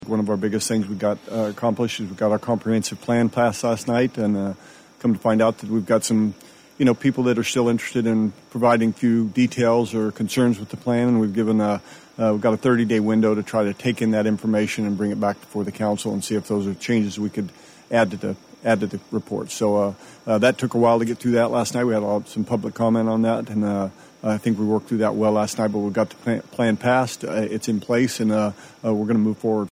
Bartlesville’s Vice Mayor Jim Curd appeared on KWON's City Matters program t talk about the approval of the city's comprehensive plan, recent Fair Labor Standards Act adjustments for city employees, and upcoming community initiatives.